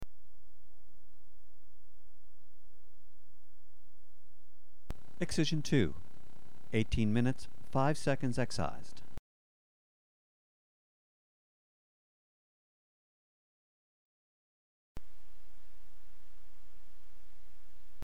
Sound recording of a meeting held on August 15, 1963, between President John F. Kennedy and several military and intelligence advisors regarding British Guiana.
Secret White House Tapes | John F. Kennedy Presidency Meetings: Tape 104/A40.